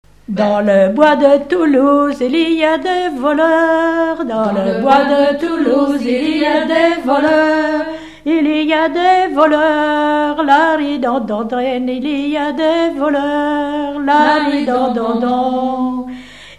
Mémoires et Patrimoines vivants - RaddO est une base de données d'archives iconographiques et sonores.
Usage d'après l'informateur gestuel : danse ;
Genre laisse
Catégorie Pièce musicale inédite